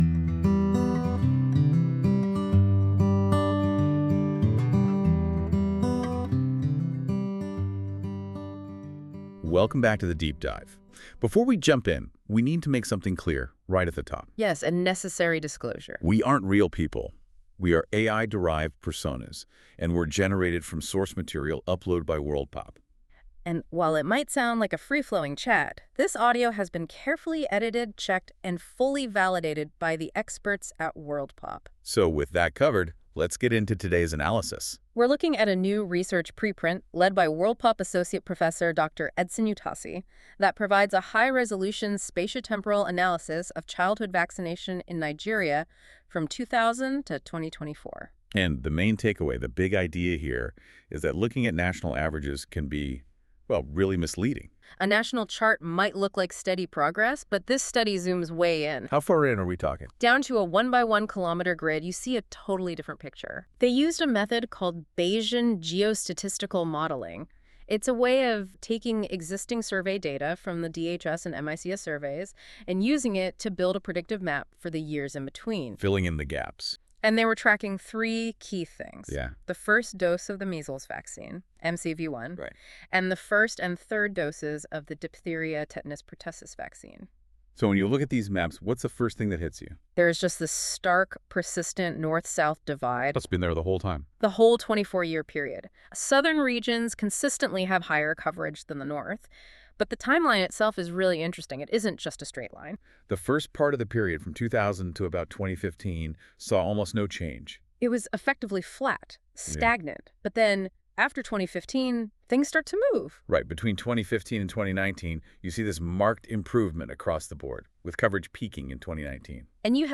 This feature uses AI to create a podcast-like audio conversation between two AI-derived hosts that summarise key points of documents - in this case the full preprint article linked above.
Music: My Guitar, Lowtone Music, Free Music Archive (CC BY-NC-ND)